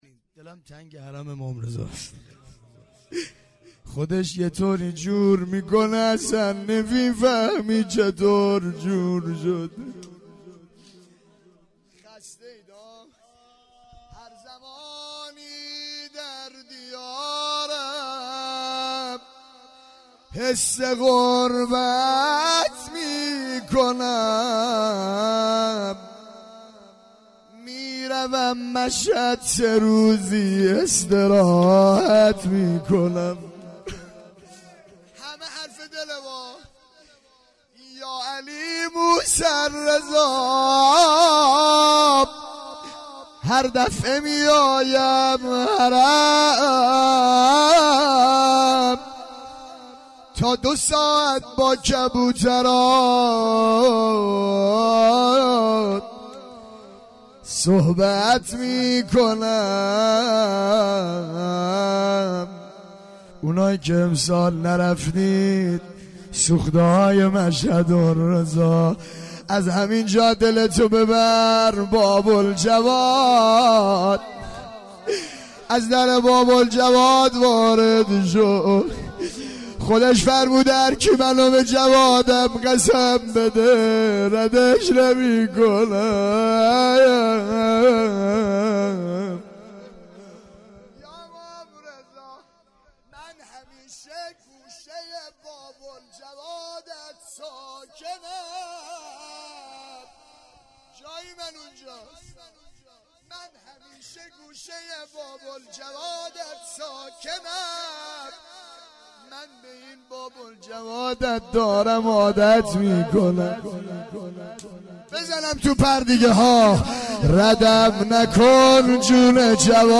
هیئت زواراباالمهدی(ع) بابلسر - روضه پایانی